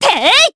Yanne-Vox_Attack3_jp.wav